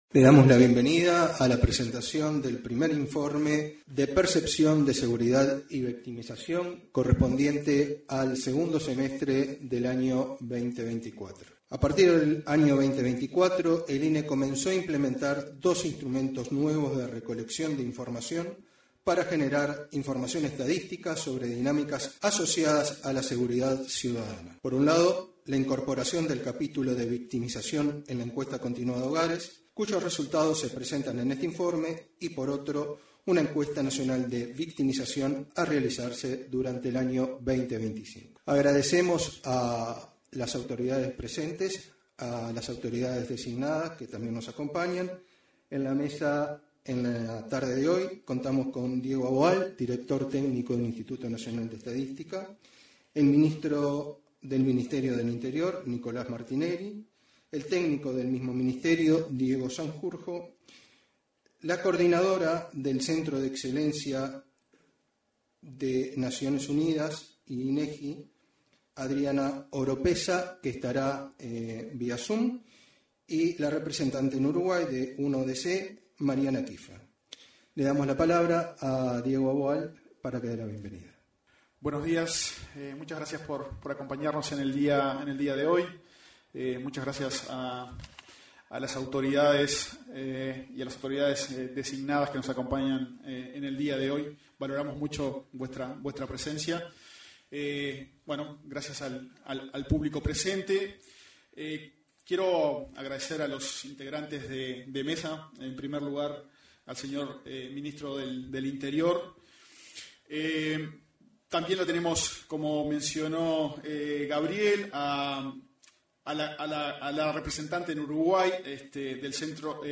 Este jueves 13, se realizó, en el salón de actos de la Torre Ejecutiva, la presentación del primer informe semestral de estadísticas de victimización,
En la oportunidad, se expresaron el director de esa dependencia, Diego Aboal; el coordinador de Estrategias de Seguridad Integral y Preventiva del Ministerio del Interior, Diego Sanjurjo, y el titular de dicha cartera, Nicolás Martinelli.